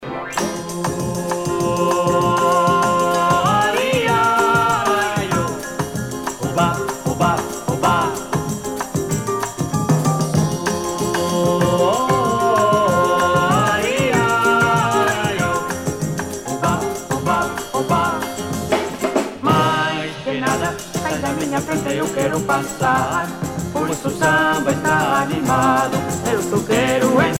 danse : bossa nova